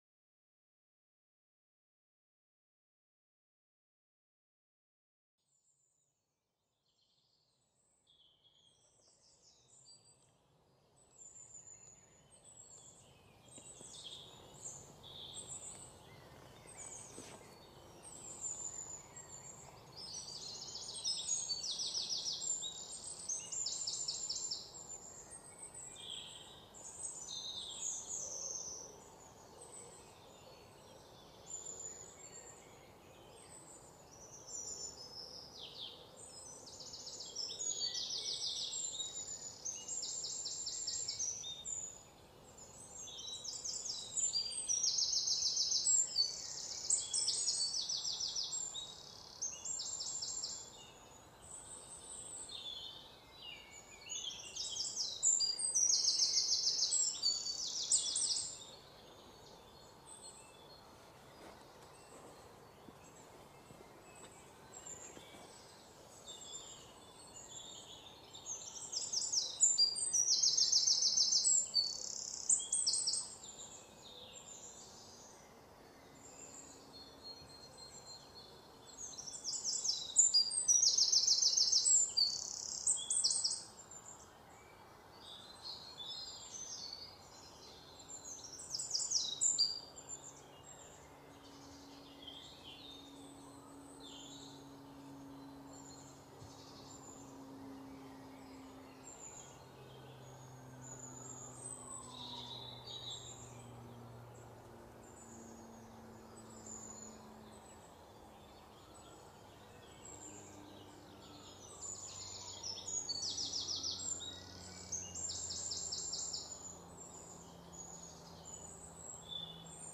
forestysounds.mp3